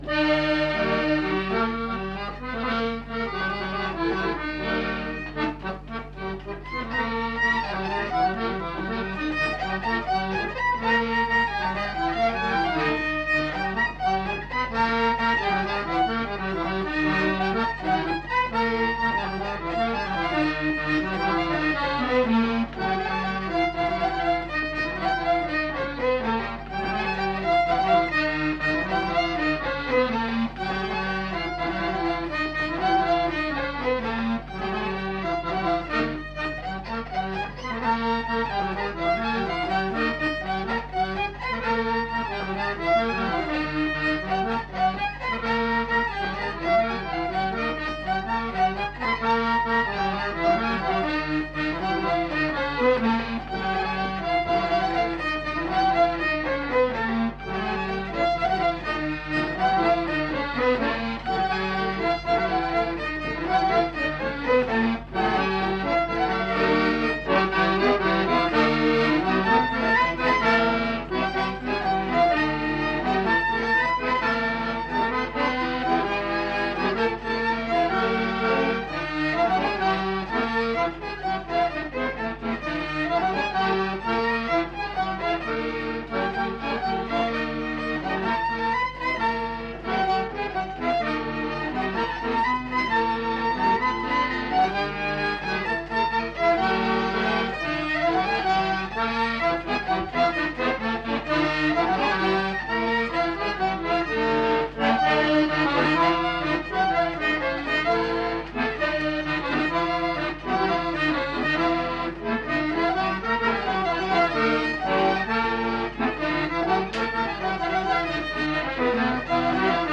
Répertoire de danses des Petites-Landes interprété au violon et à l'accordéon chromatique
Rondeau